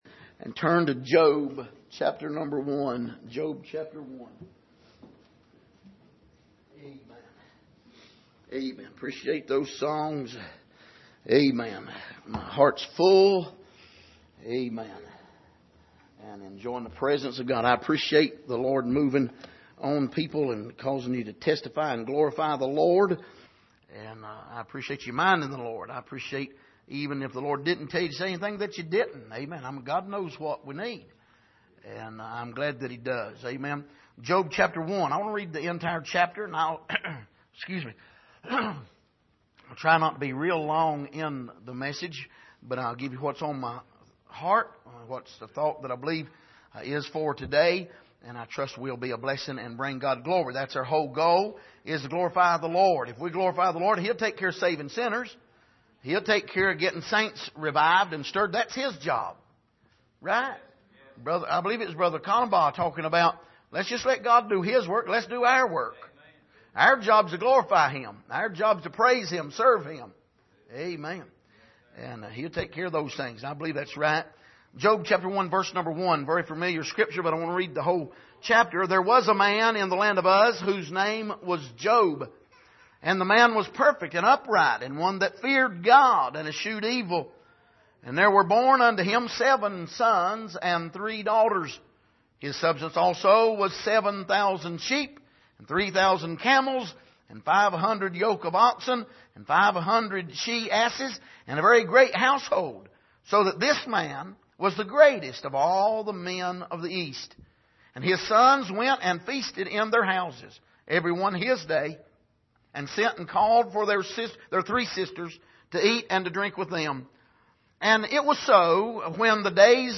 Passage: Job 1:1-22 Service: Sunday Morning